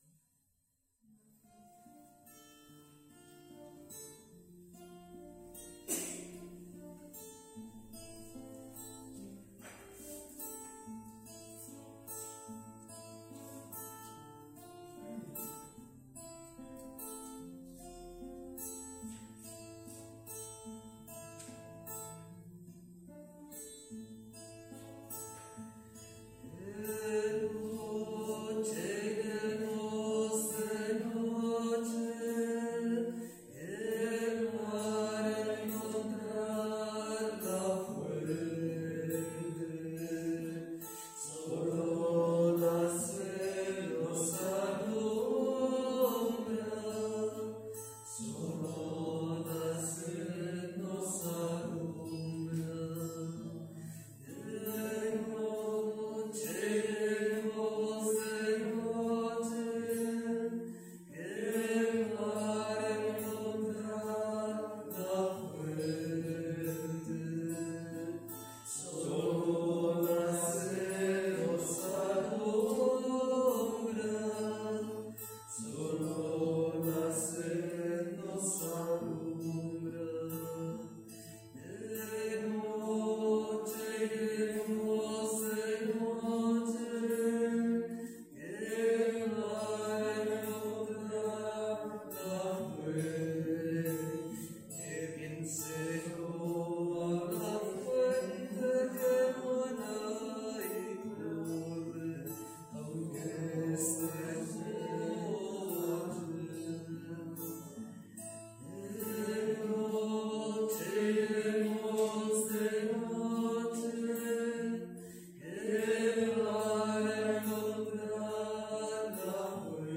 Pregària de Taizé a Mataró... des de febrer de 2001
Ermita de Sant Simó - Diumenge 30 de gener de 2022